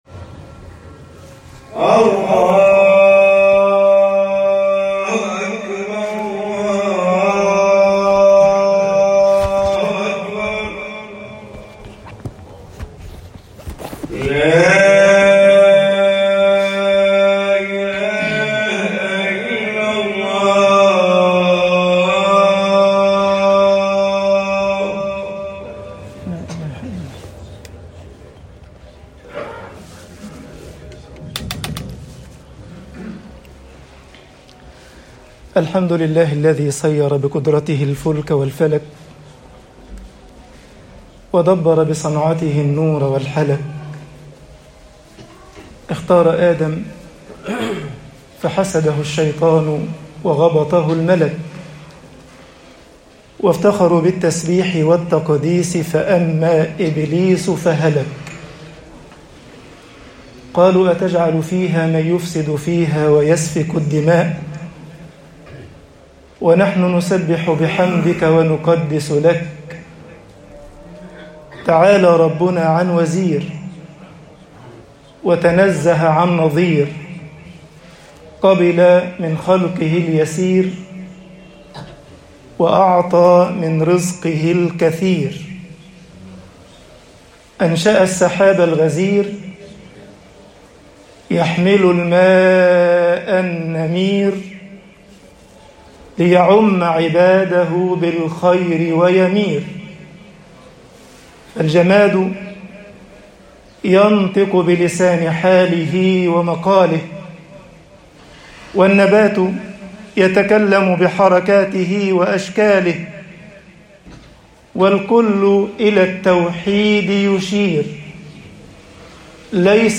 خطب الجمعة - مصر توفَّني مُسْلِمًا طباعة البريد الإلكتروني التفاصيل كتب بواسطة